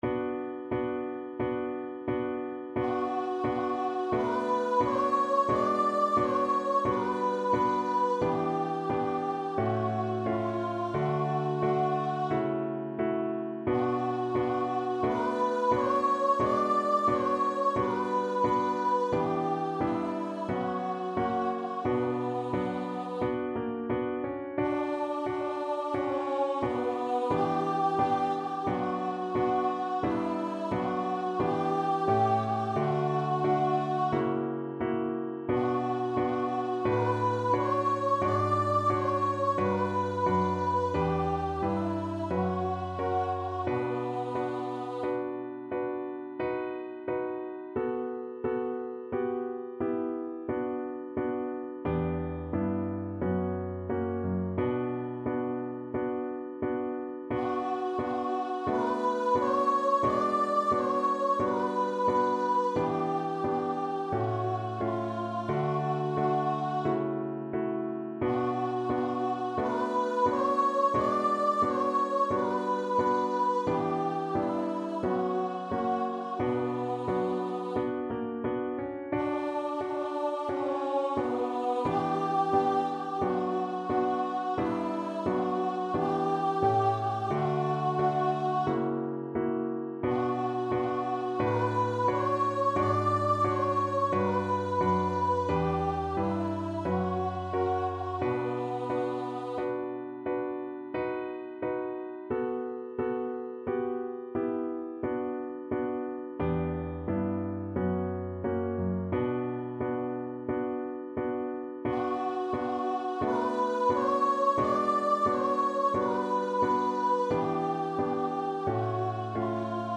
Voice
Traditional Music of unknown author.
Andante =c.88
kojo_no_tsuki_VOICE.mp3